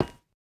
Minecraft Version Minecraft Version snapshot Latest Release | Latest Snapshot snapshot / assets / minecraft / sounds / block / iron / step2.ogg Compare With Compare With Latest Release | Latest Snapshot
step2.ogg